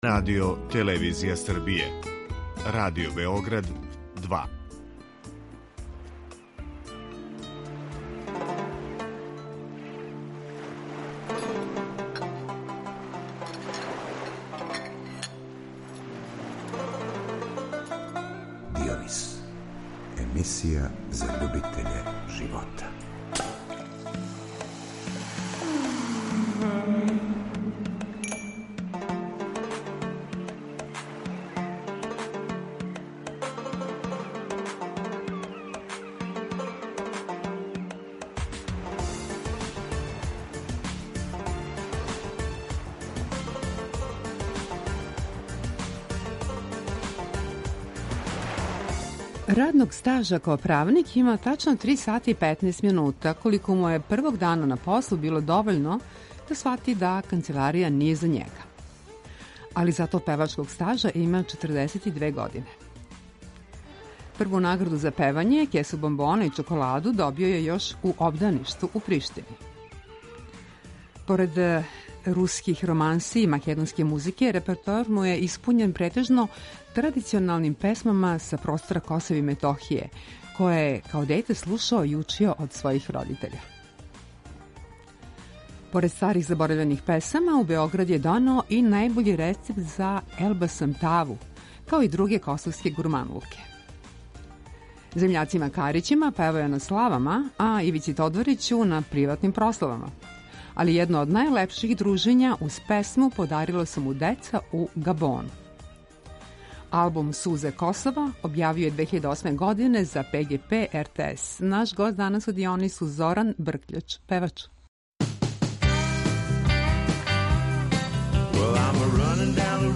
Извор: Радио Београд 2